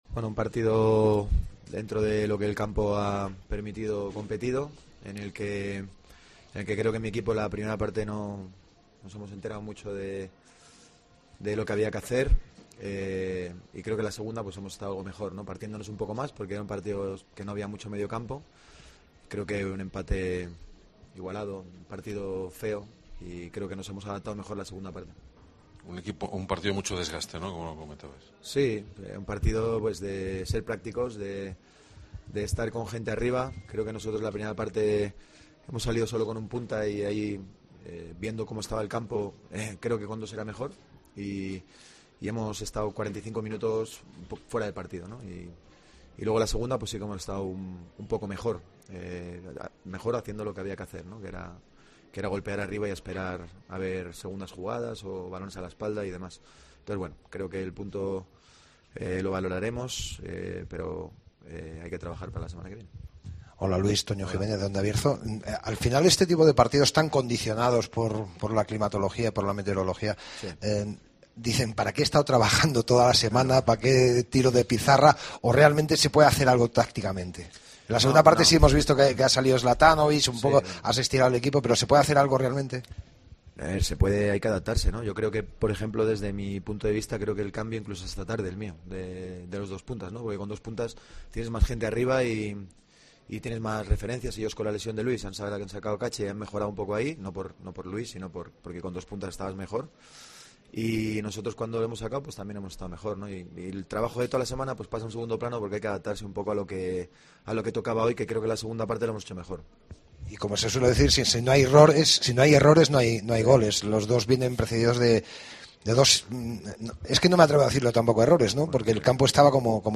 Escucha aquí el postpartido con declaraciones